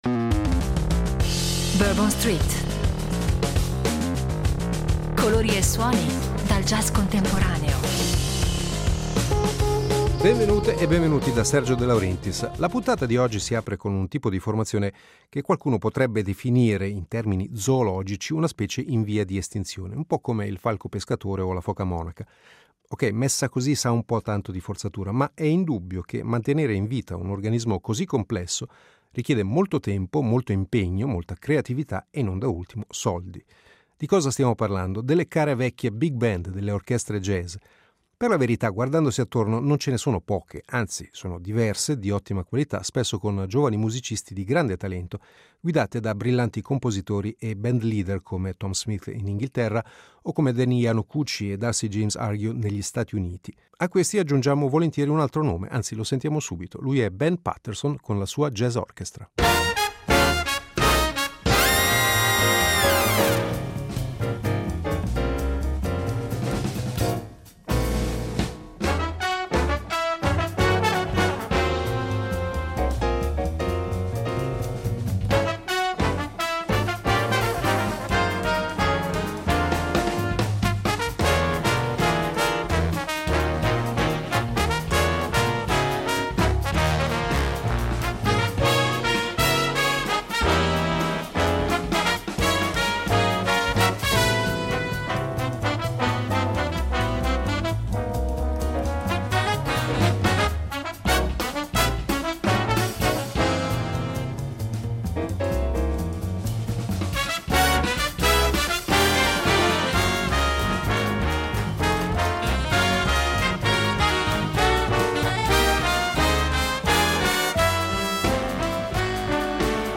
Big Band Jazz